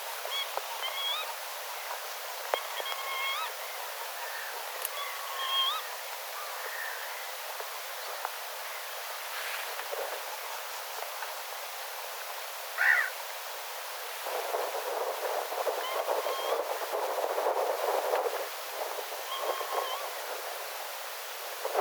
erikoista töyhtöhyypän ääntä
erikoista_toyhtohyypan_aanta.mp3